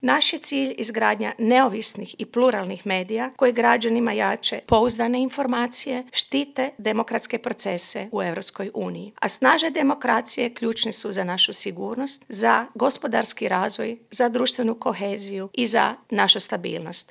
O svemu tome razgovaralo na konferenciji koja je okupila novinare, urednike, medijske stručnjake, ali i studente.
Ravnatelj Agencije za elektroničke medije Josip Popovac: